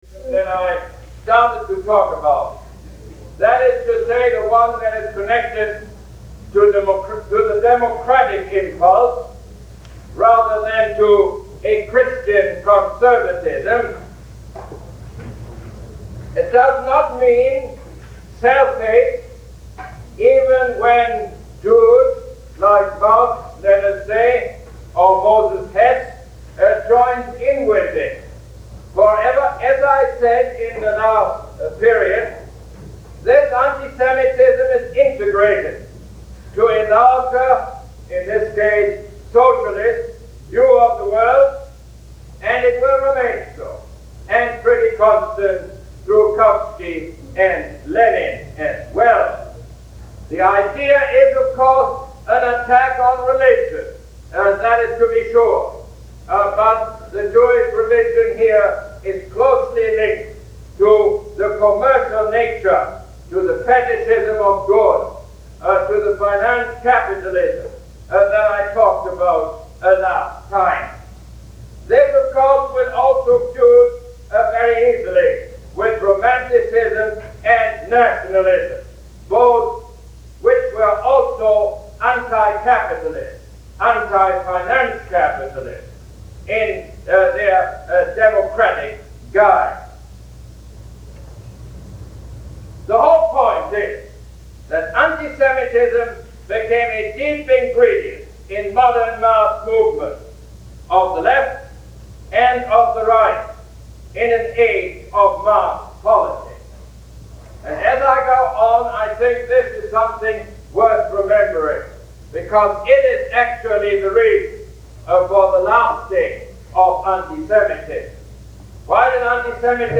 Lecture #8 - March 3, 1971